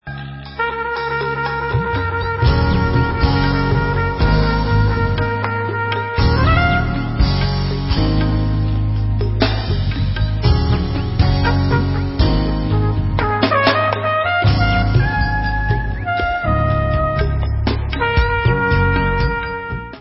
sledovat novinky v oddělení Jazz